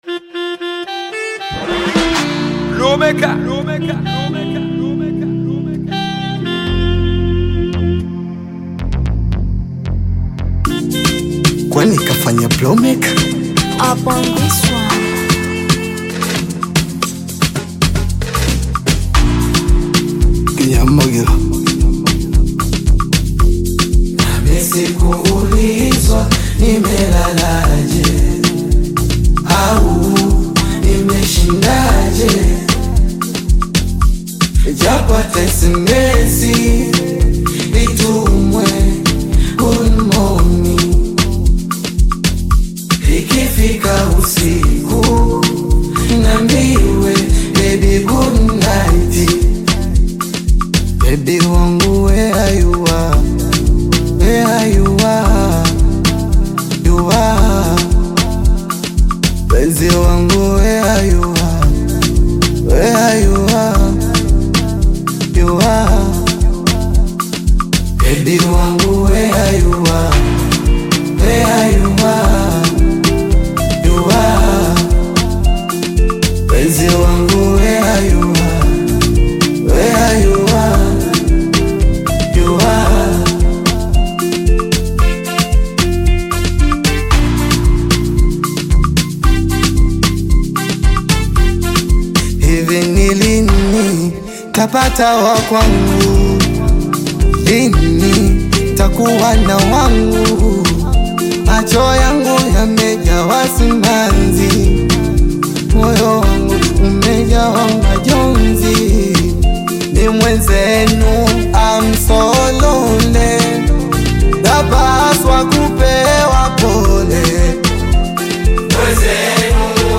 Bongo Flava music track
Tanzanian Bongo Flava artist, singer, and songwriter
Bongo Flava song